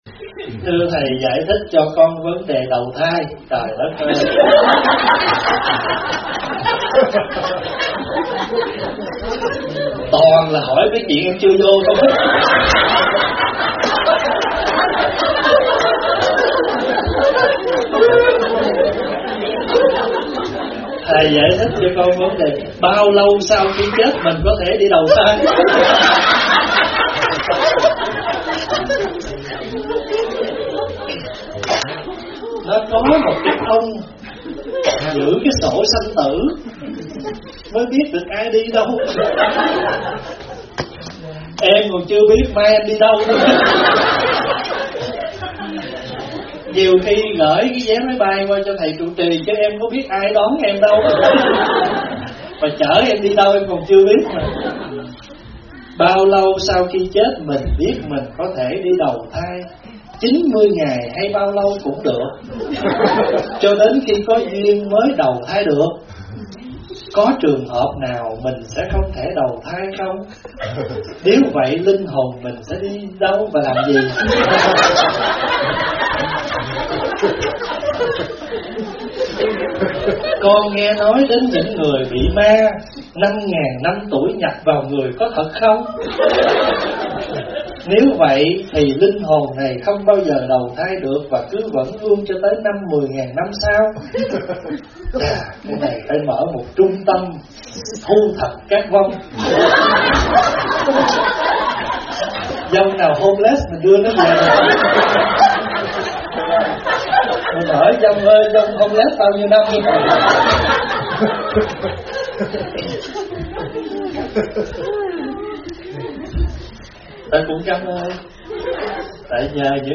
Nghe Mp3 thuyết pháp Đi Đầu Thai - ĐĐ. Thích Pháp Hòa
Mời quý phật tử nghe mp3 vấn đáp Đi Đầu Thai do ĐĐ. Thích Pháp Hòa giảng